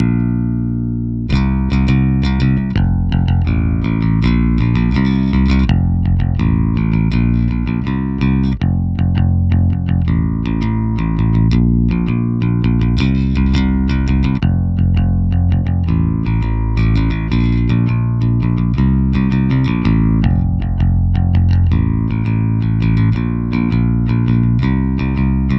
navy_electricbass.flac